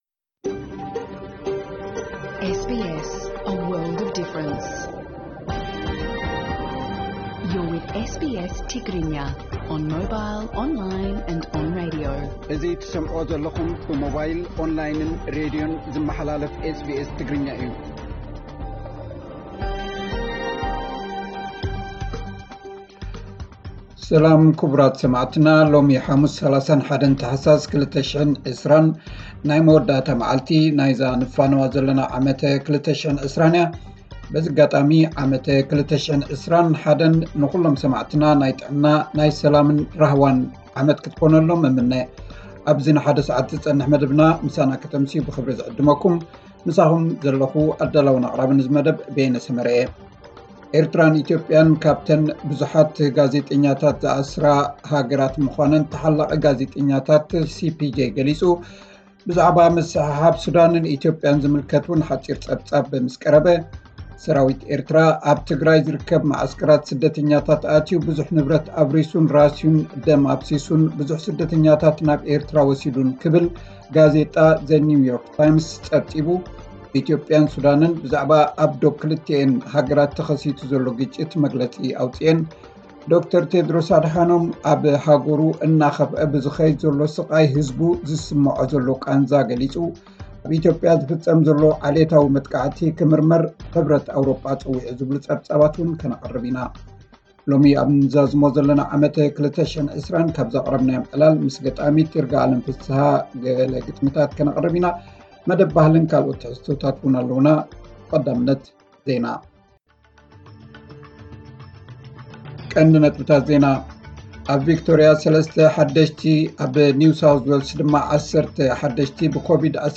ዕለታዊ ዜና 31 ታሕሳስ 2020 SBS ትግርኛ